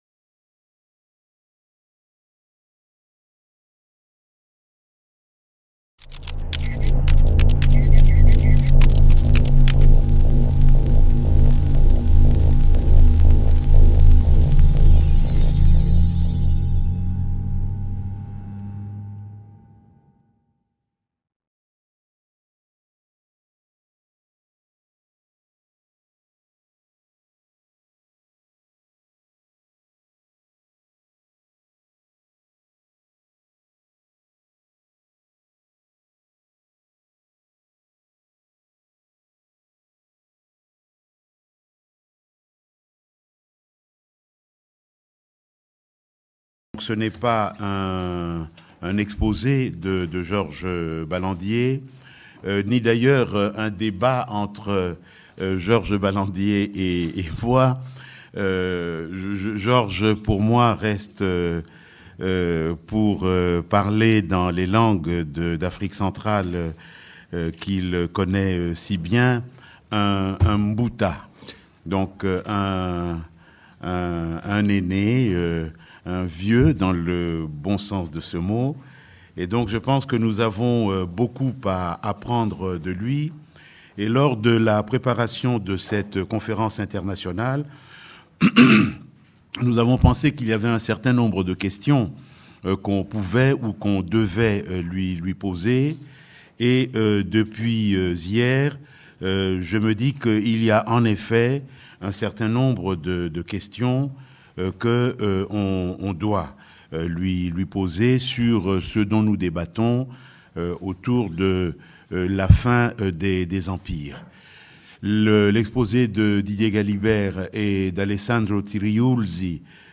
2/B Débat avec Georges Balandier/Il était une fois.
Il était une fois. Les indépendances africaines… La fin des empires ? Rencontre internationale